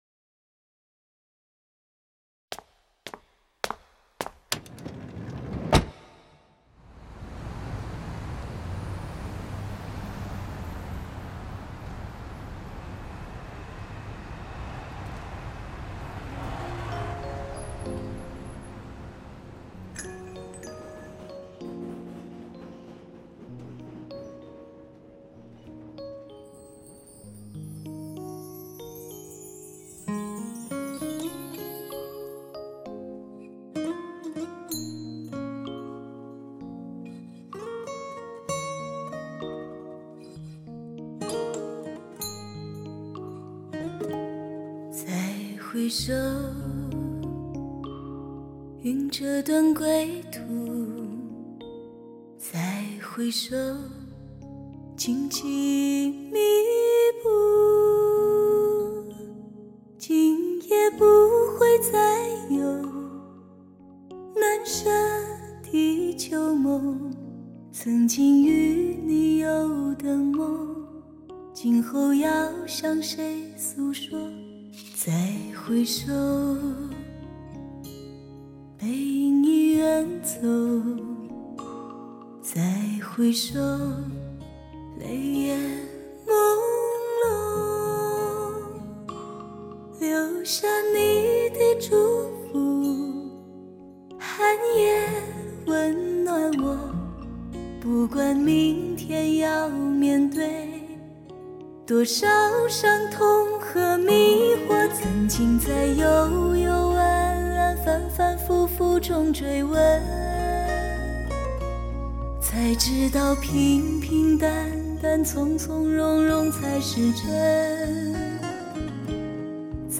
在朴实自然中的细腻演绎，听者悠然神往